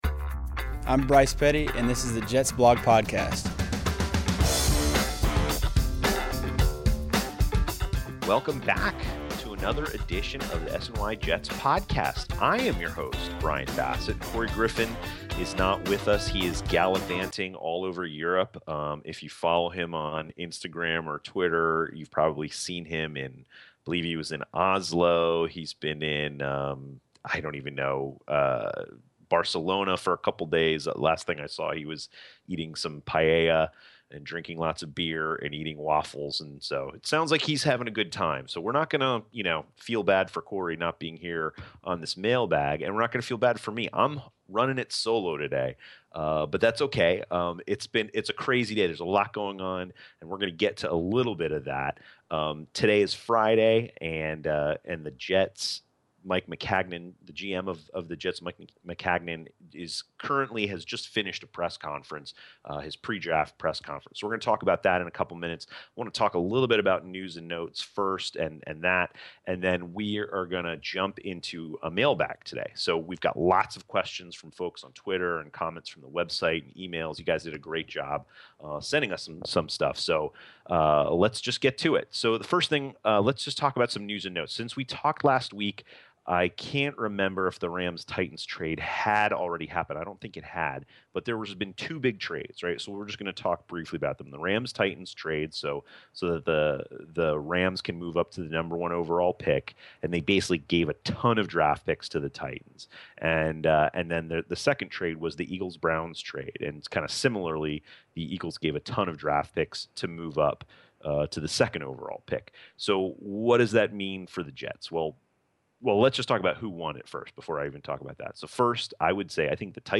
takes the mic solo and delivers the show with an assist from the readers and listeners. The Mailbag is busted open to cover all kinds of topics…such as quarterback, Muhammad Wilkerson, the salary cap, the NFL Draft, and more.